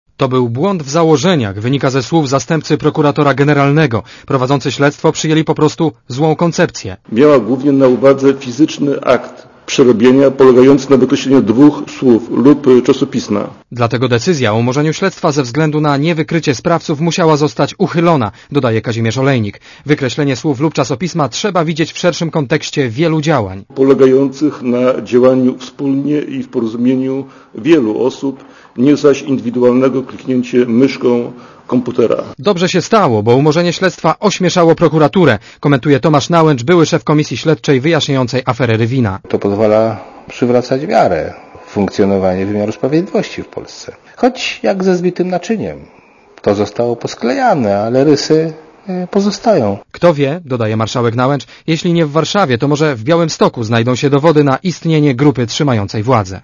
Relacja reportera Radia ZET Śledztwo w sprawie fałszerstwa ustawy o rtv, prowadzone od lipca 2003 r., umorzono w czwartek z powodu nie wykrycia sprawców.